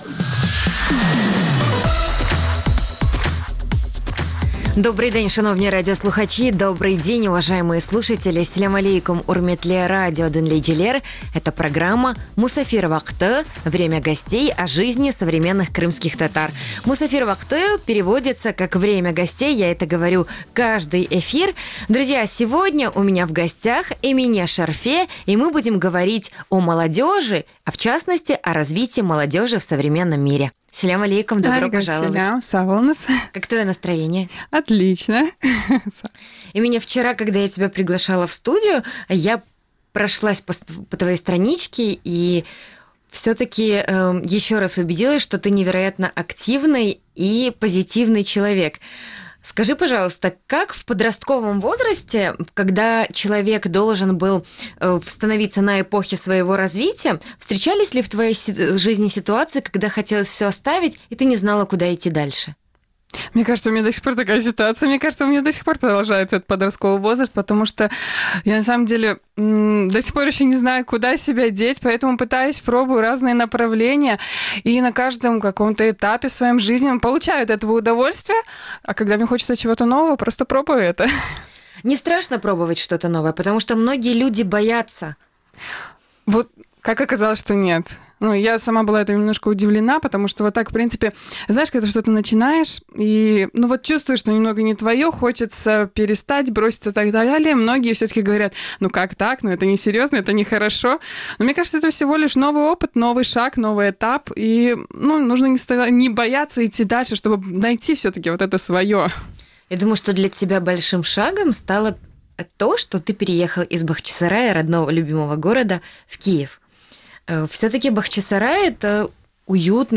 Эфир можно слушать Крыму в эфире Радио Крым.Реалии (105.9 FM), а также на сайте Крым.Реалии